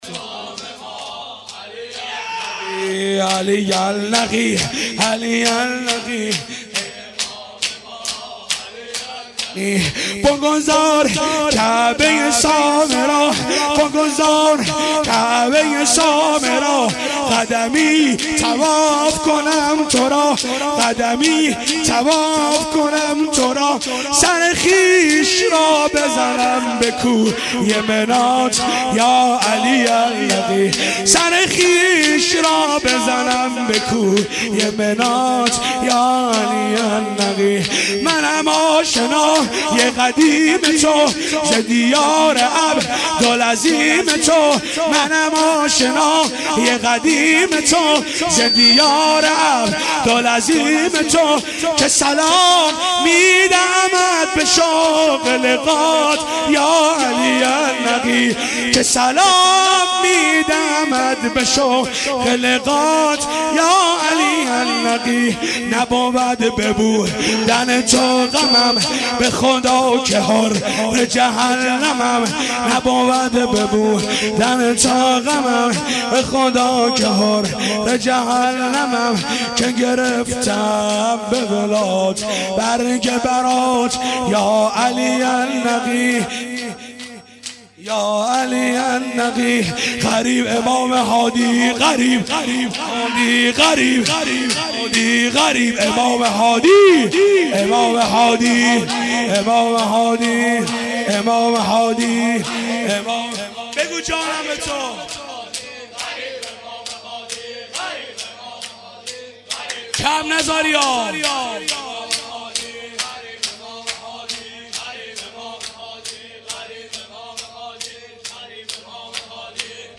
یا علی النقی (ع) (سینه زنی/شور) هیئت بیت الاحزان حضرت زهرا (س)/اهواز